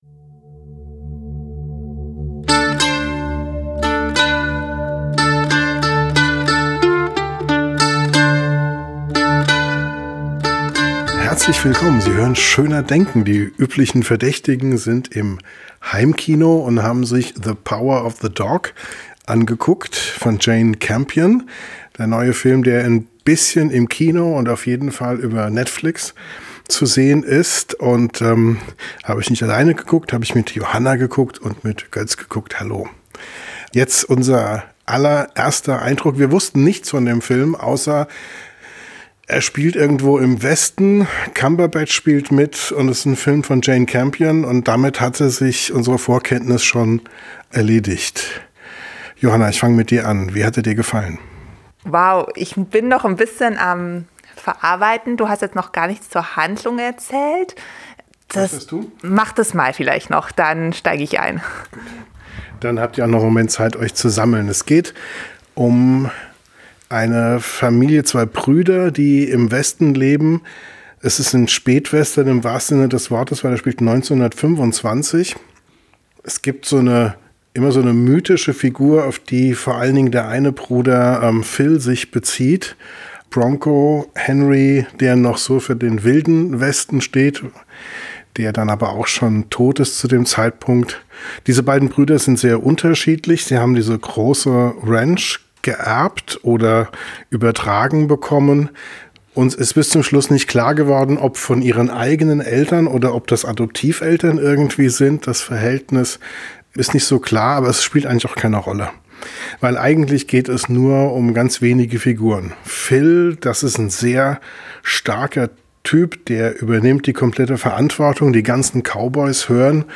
Am Mikrofon direkt nach dem Film: